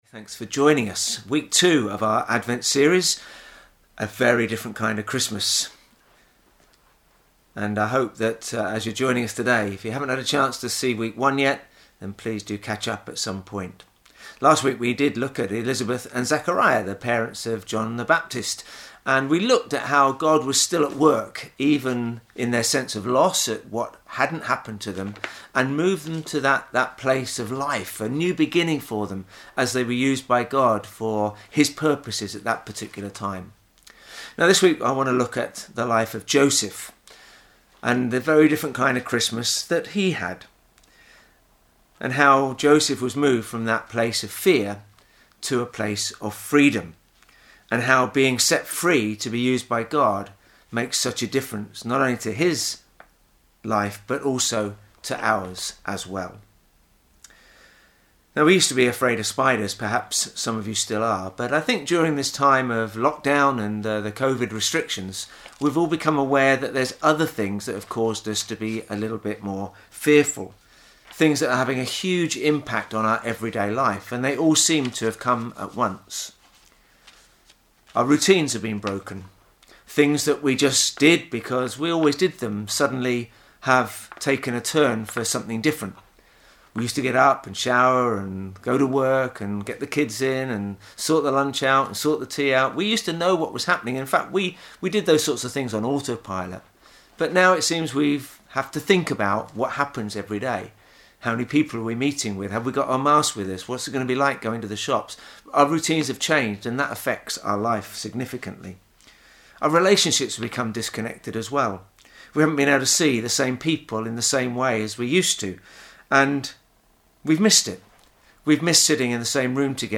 A Very Different Kind Of Christmas Watch Listen play pause mute unmute Download MP3 Thanks for joining us for our Sunday service. Today, we're continuing with our Advent series for 2020 as we look at 'A Very Different Kind Of Christmas'.